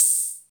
Index of /90_sSampleCDs/Roland L-CD701/DRM_Drum Machine/KIT_CR-78 Kit
HAT CR78 H03.wav